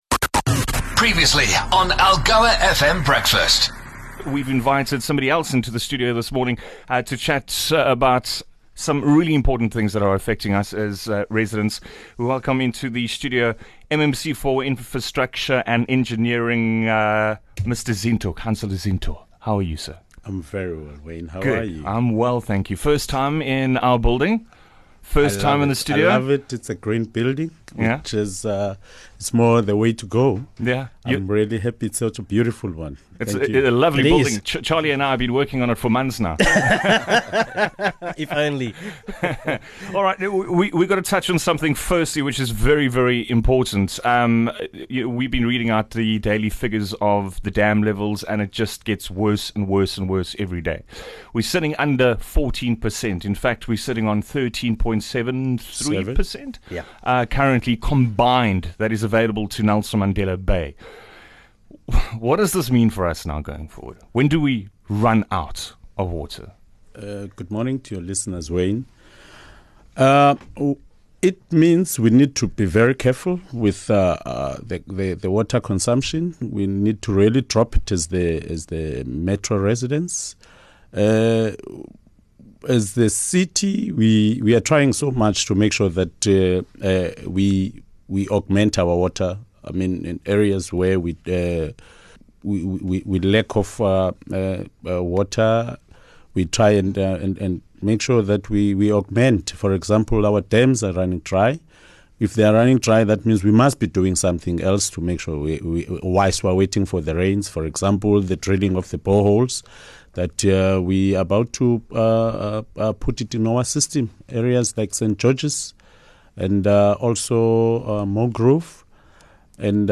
Councillor Masixole Zinto, the infrastructure boss at NMB municipality joined the breakfast team to answer some pressing questions.